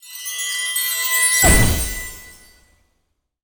magic_sparkle_chimes_explode.wav